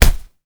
kick_soft_jab_impact_01.wav